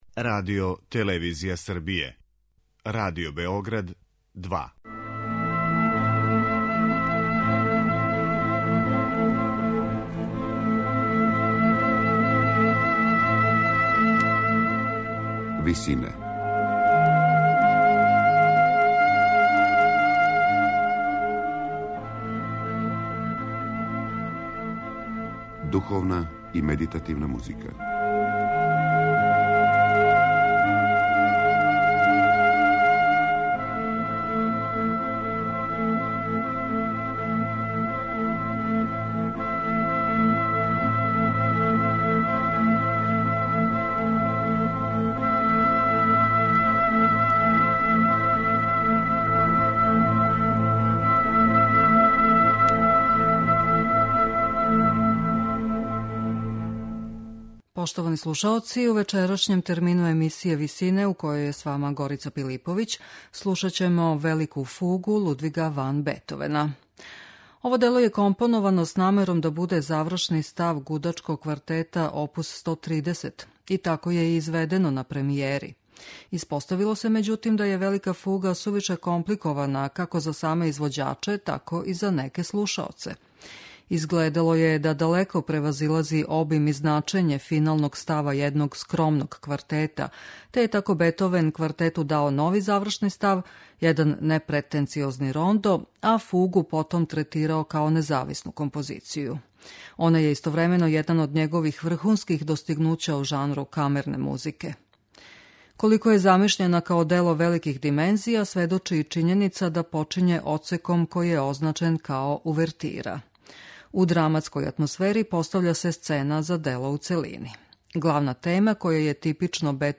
која је првобитно била завршни став Гудачког квартета опус 130
у ВИСИНАМА представљамо медитативне и духовне композиције аутора свих конфесија и епоха.